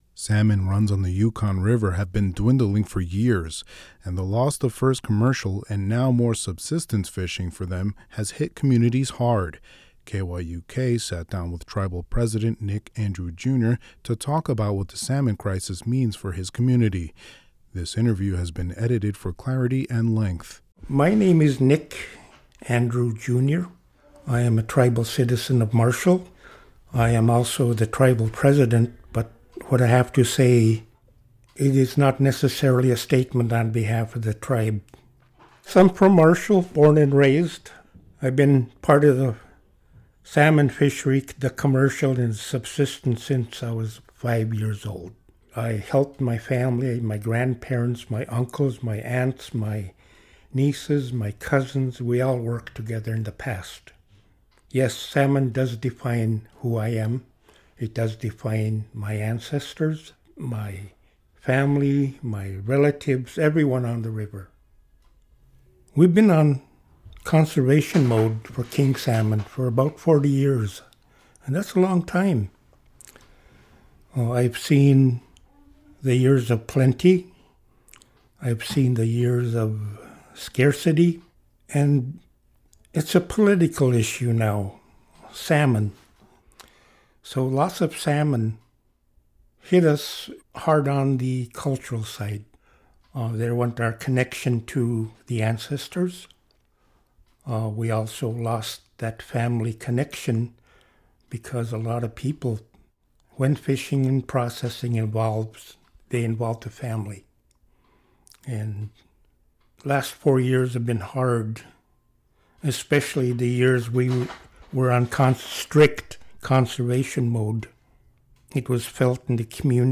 This interview has been edited for clarity and length.